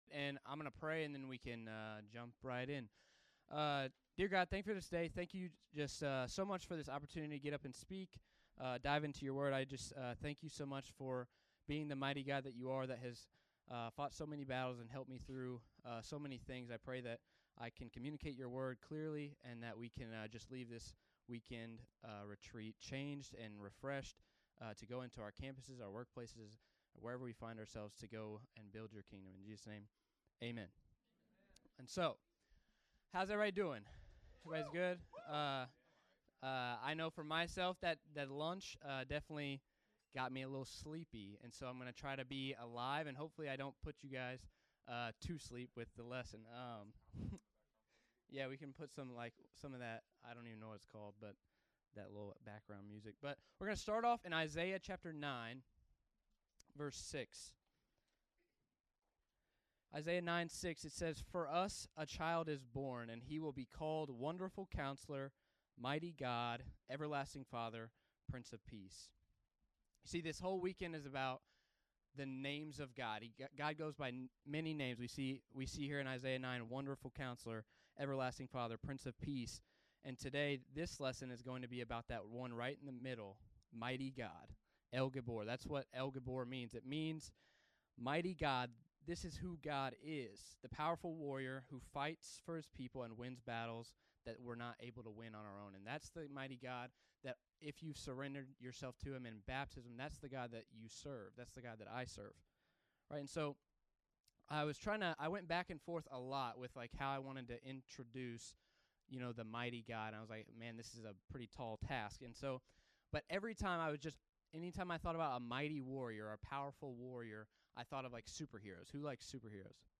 Series: "I AM" Refresh Retreat 2026